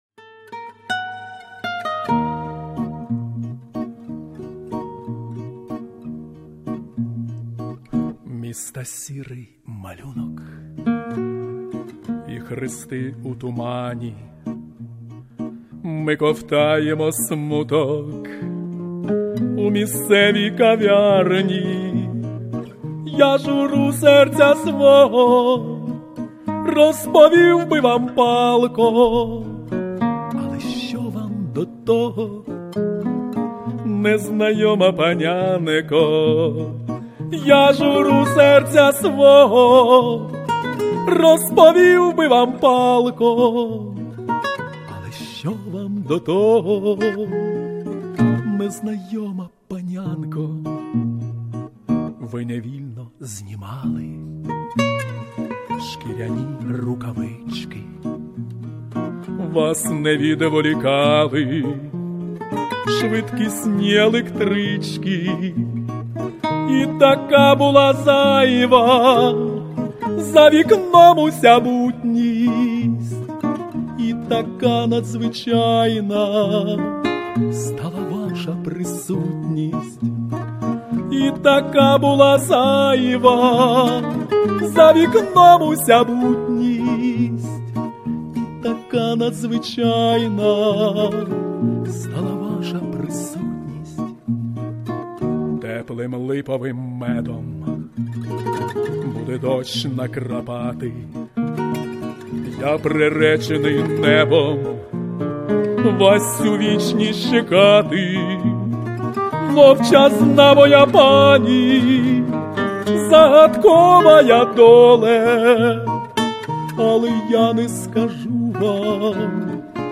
Авторська пісня